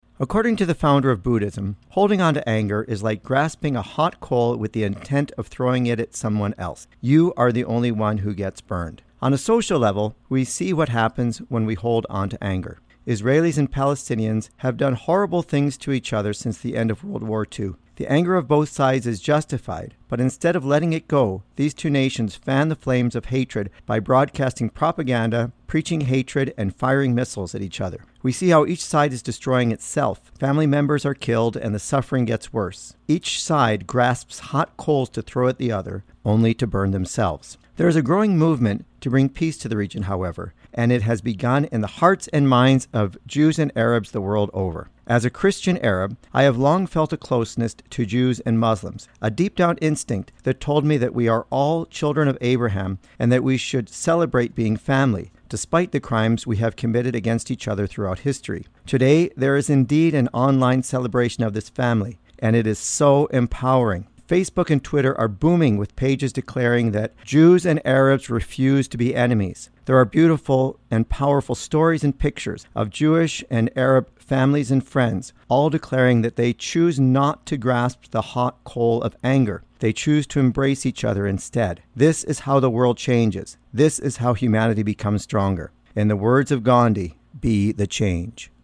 Recording Location: CFIS-FM, Prince George
Type: Commentary